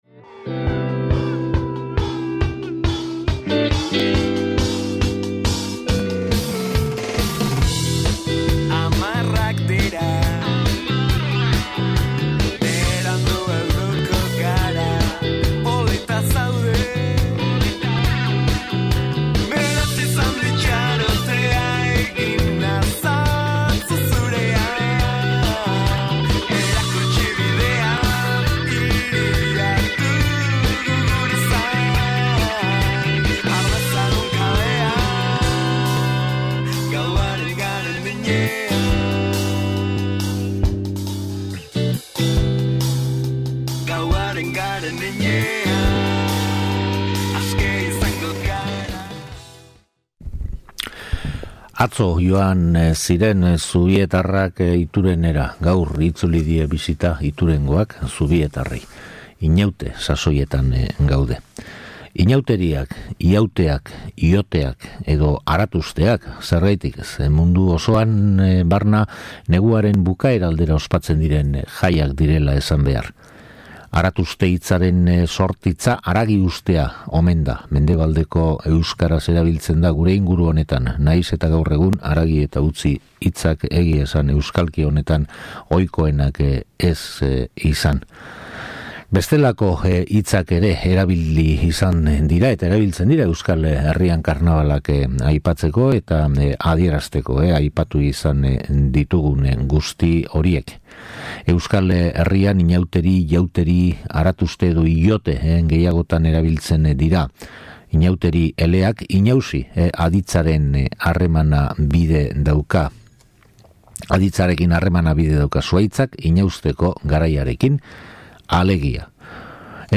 SOLASALDIA
Pertsonaiak, ohiturak, kantak…. Bakiotzetik jasotako musika eta giroa ere entzungai duzu entzule.